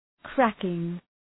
Προφορά
{‘krækıŋ}
cracking.mp3